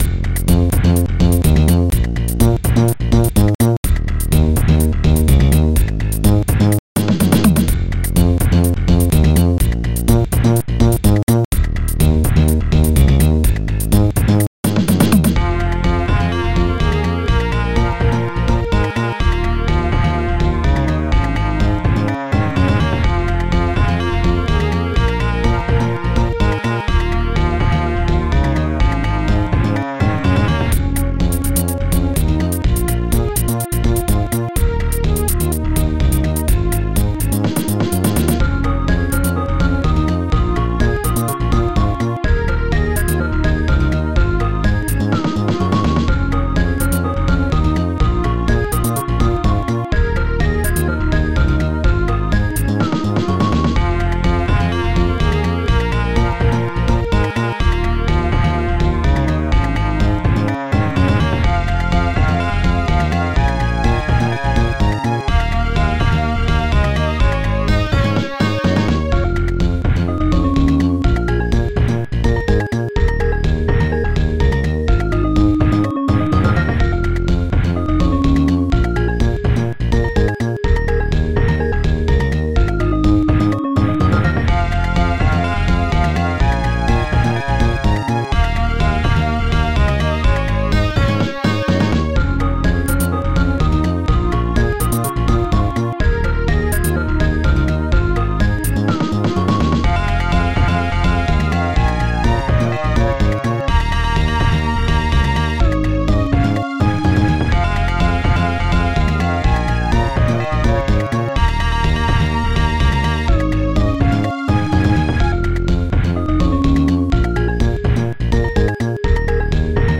st-01:slapbass
st-01:bassdrum3
st-01:snare1
st-01:woodblock
st-01:metalkeys
st-01:strings3
st-01:hihat1
st-01:pingbells